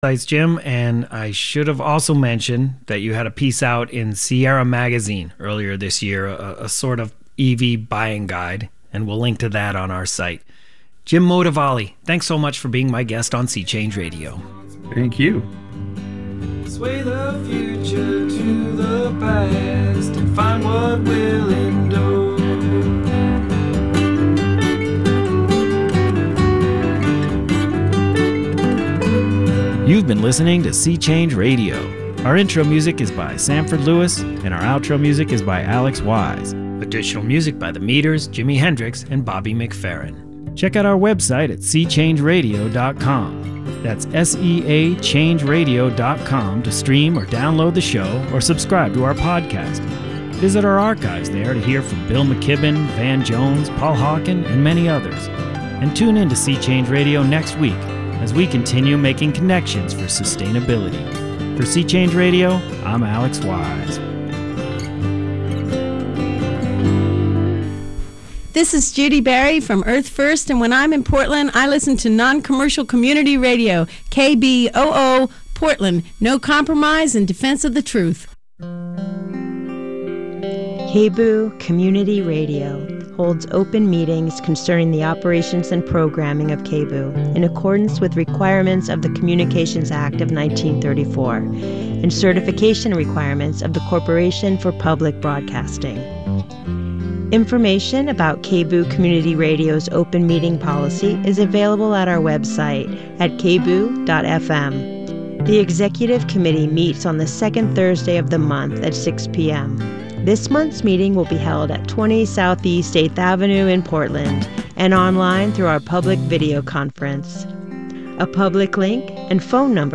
11:30am to 12:00pm A radio show about visual art.